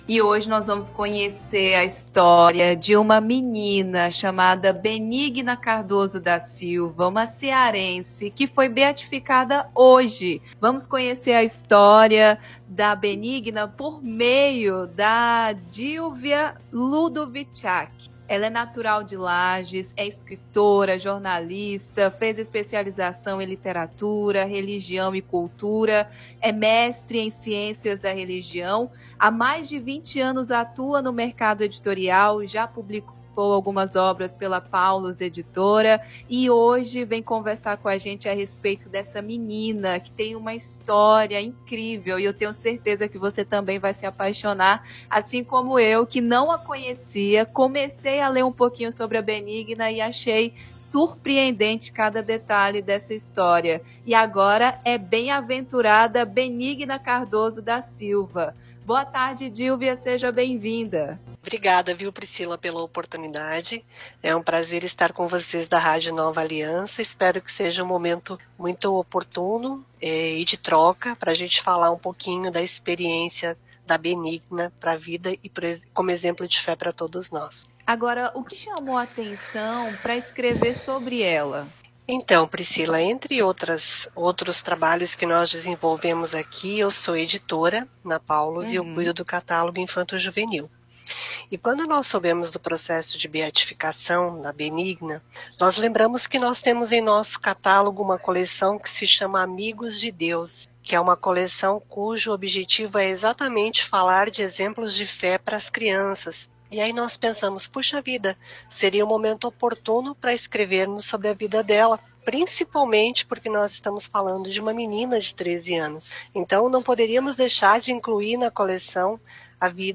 Veículo: Rádio Nova Aliança Data: 24/10/2022